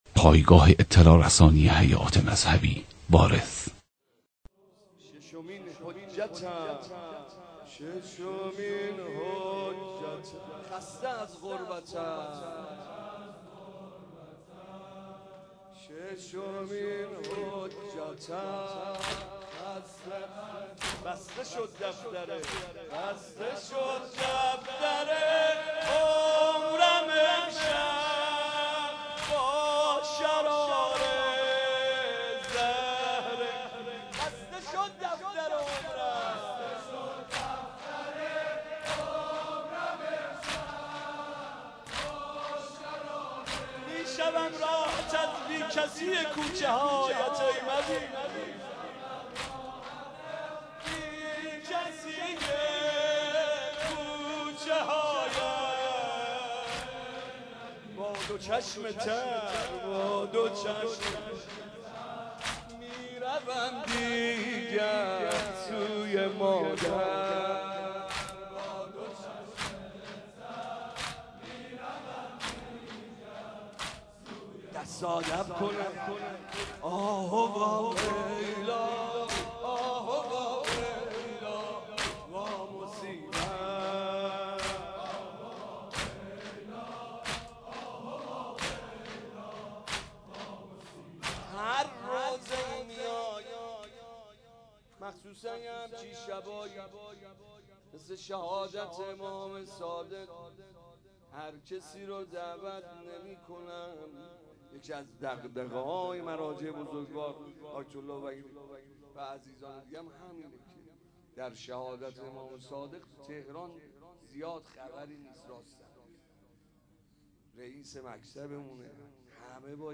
مداحی حاج محمد رضا طاهری به مناسبت شهادت امام صادق (ع)
دانلود صوت نوحه شهادت امام صادق ع حاج محمد رضا طاهری وارث اخبار مرتبط انقلاب درونی مناجات خوان معروف تهران نماز عید فطر چگونه است؟